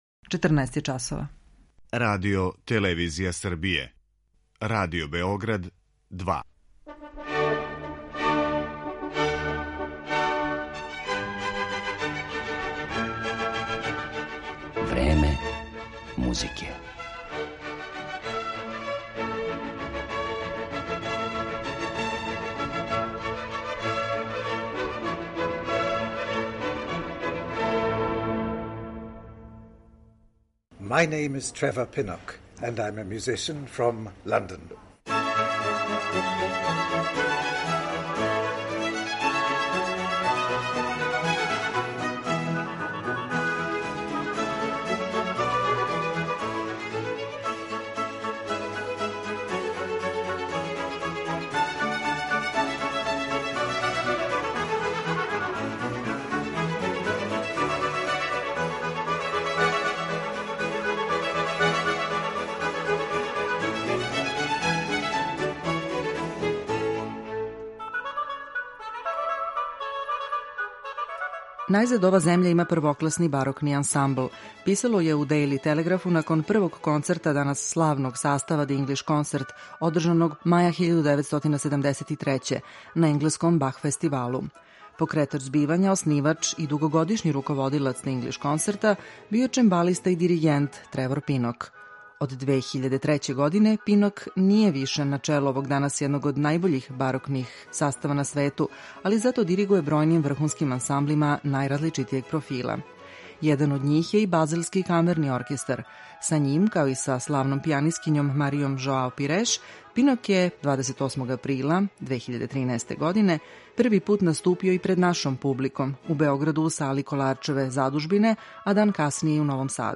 У Времену музике ћемо га ипак претежно представити у репертоару са којим се прославио и слушаћете га како изводи дела барокних мајстора. Емитоваћемо и еклузивни интервју снимљен са њим у Београду априла 2013. године.